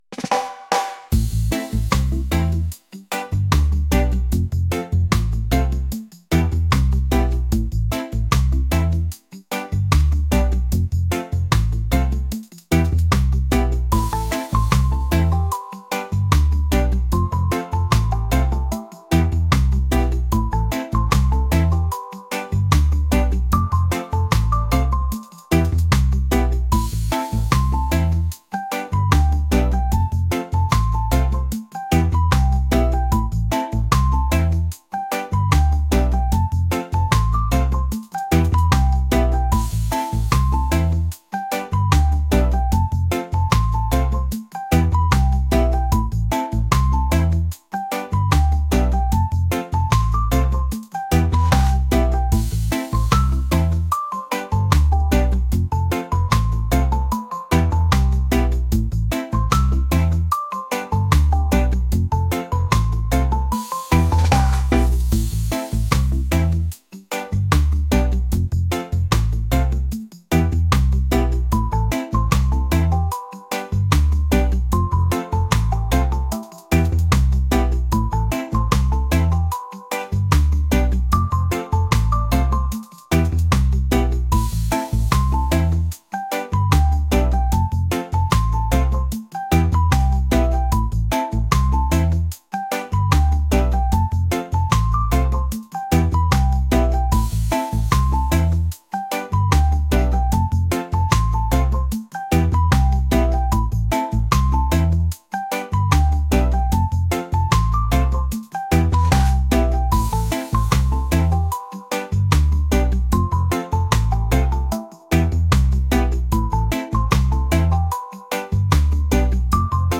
reggae | lofi & chill beats | ambient